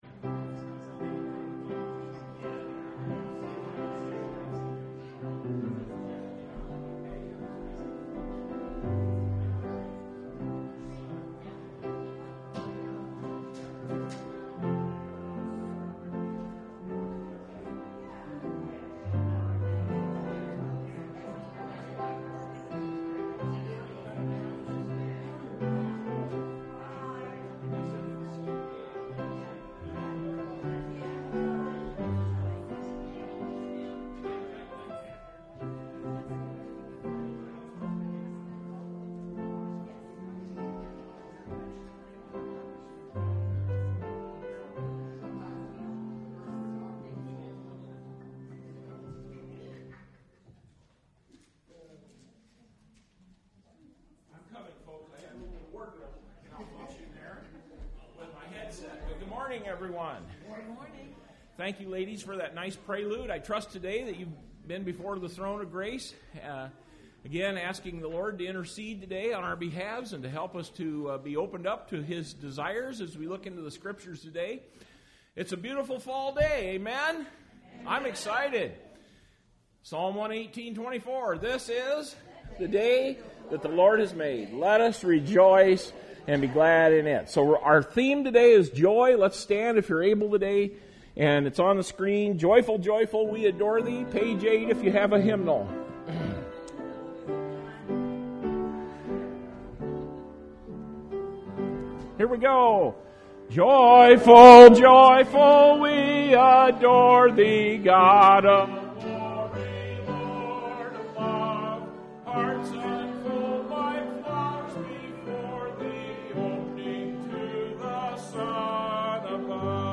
Service Type: Sunday Morning Service Topics: Christian Living , Joy